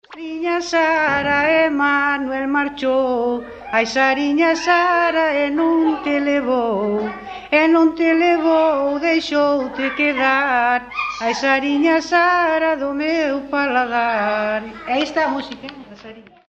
Tipo de rexistro: Musical
Áreas de coñecemento: LITERATURA E DITOS POPULARES > Coplas
Lugar de compilación: Mesía - Lanzá (San Mamede)
Soporte orixinal: Casete
Instrumentación: Voz
Instrumentos: Voz feminina